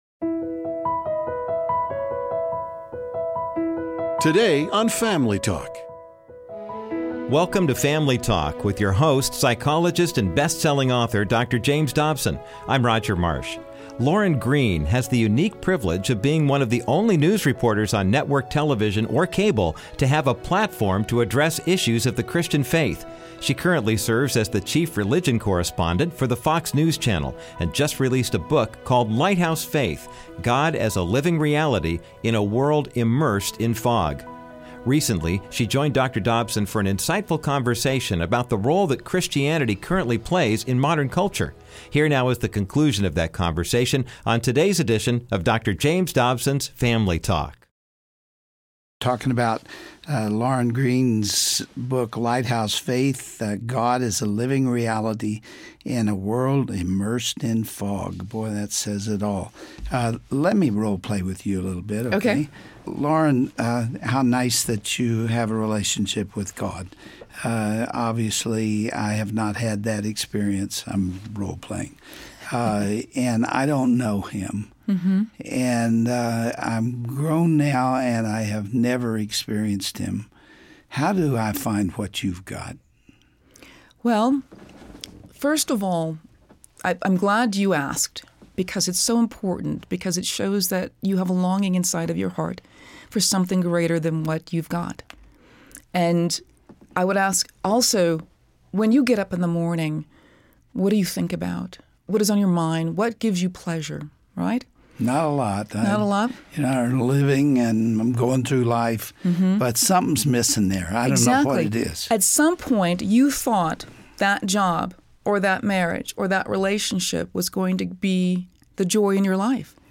Today on Family Talk, Dr. James Dobson continues his conversation with Lauren Green, Fox News Channel's chief religion correspondent. She shares helpful insights from her book, Lighthouse Faith, exploring how God serves as our beacon through life's darkest moments.